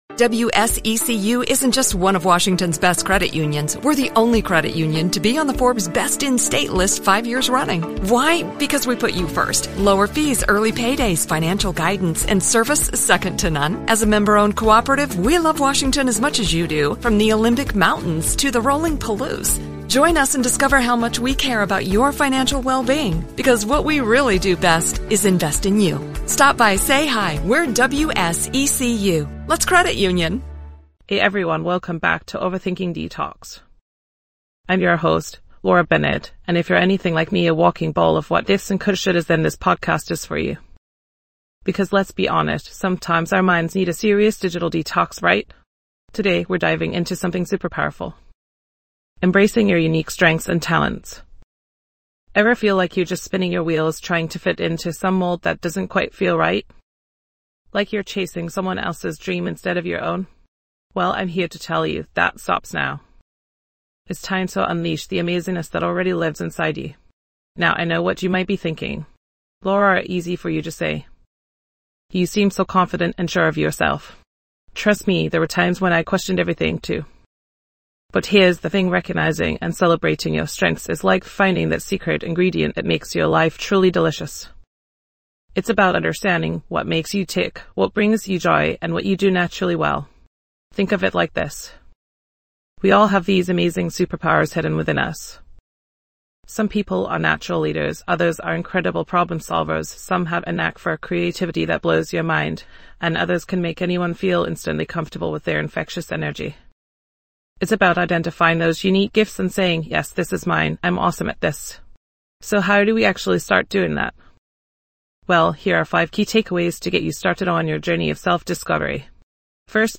Podcast Category:. Personal Development & Self-Help
This podcast is created with the help of advanced AI to deliver thoughtful affirmations and positive messages just for you.